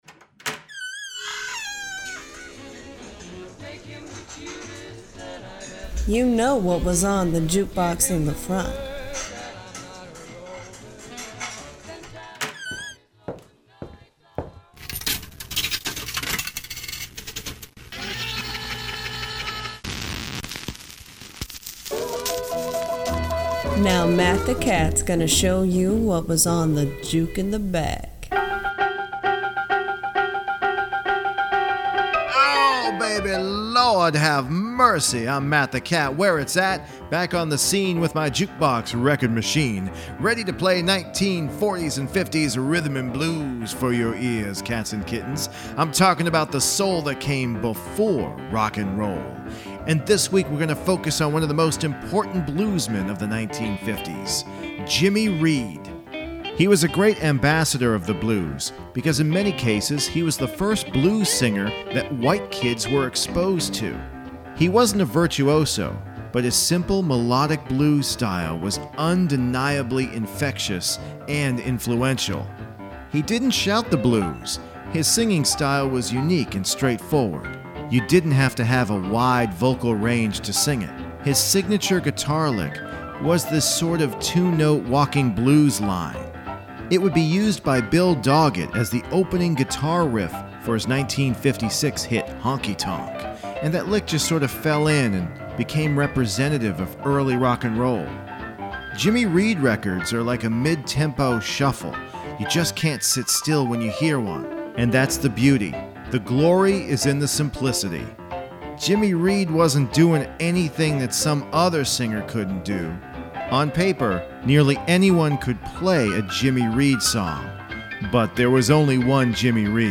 To hear all this great 1950s rhythm & blues, you had to go to “Juke In The Back.”